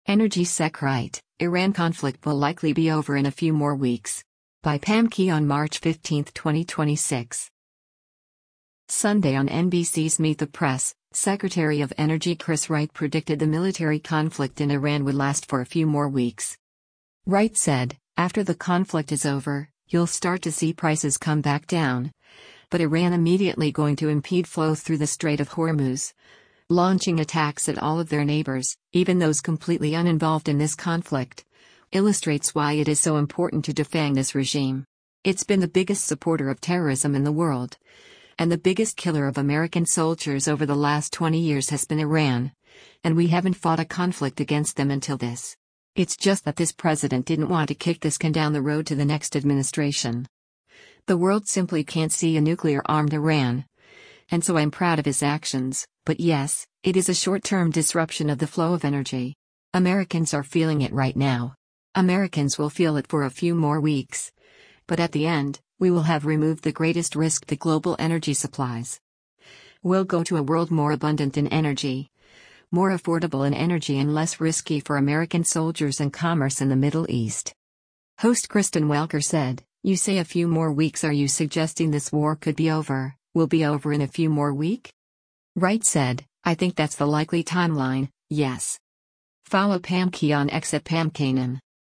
Sunday on NBC’s “Meet the Press,” Secretary of Energy Chris Wright predicted the military conflict in Iran would last for a few more weeks